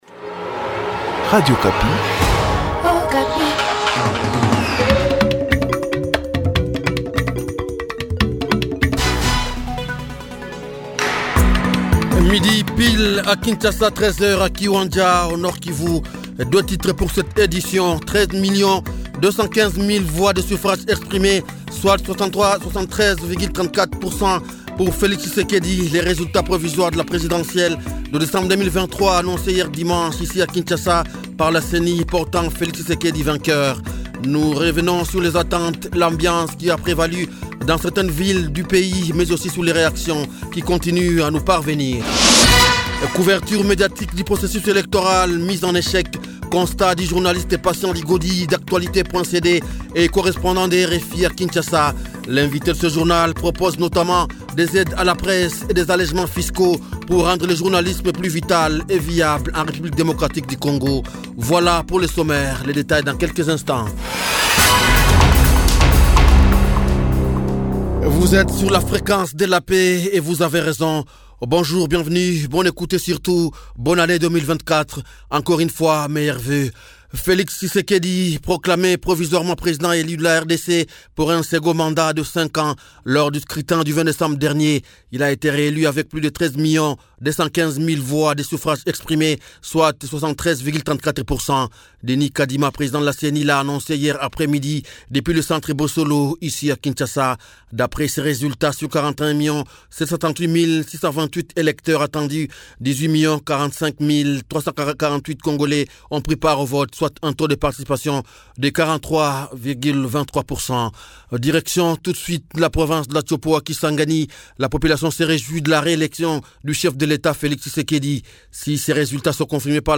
journal francais
Vox pop sur après la publication des résultats de l’élection présidentielle